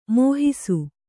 ♪ mōhisu